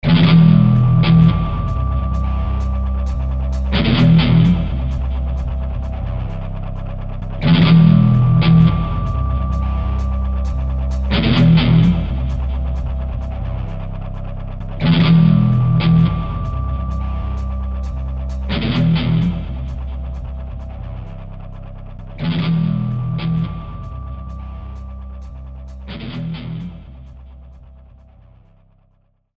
• Design sonore pour la démonstration 3D Air Races.
2 ambiences de « zones » en boucle:
redbull-loop-ambiance-zone2.mp3